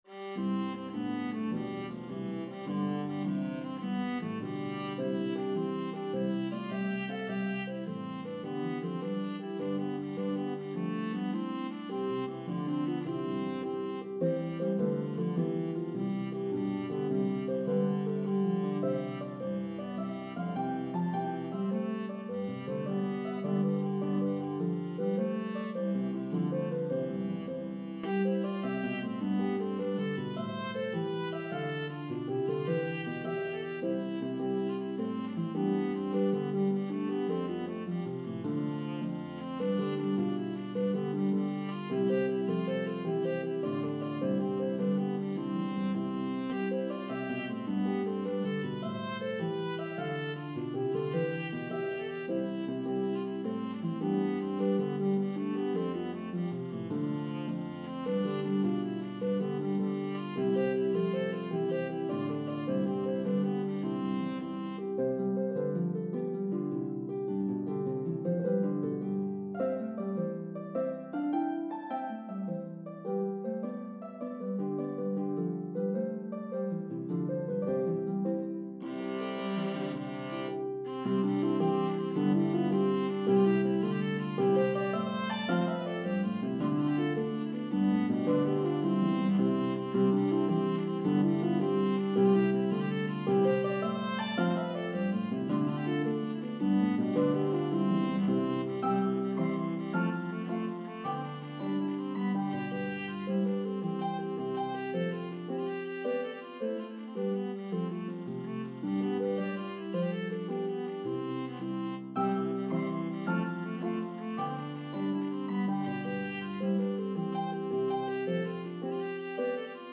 The medley progresses through 3 keys.